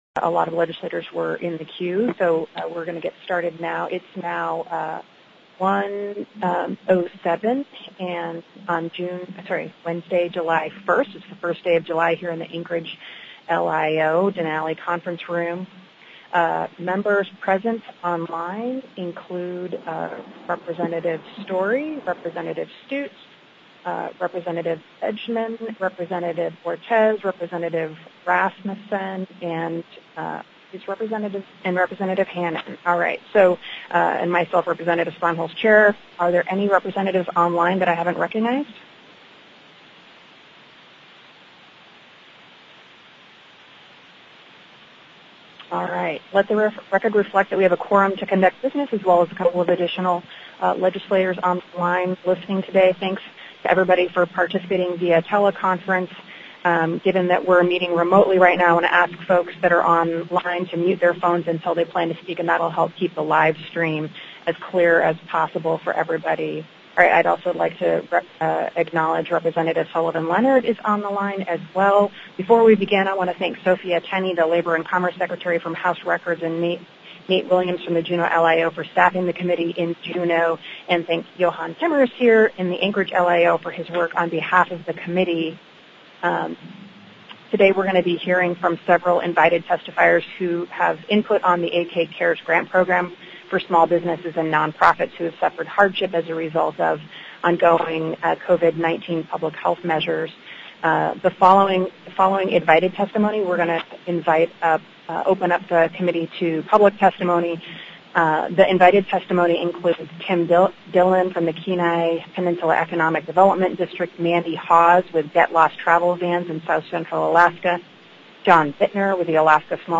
The audio recordings are captured by our records offices as the official record of the meeting and will have more accurate timestamps.
TELECONFERENCED -- Public Testimony --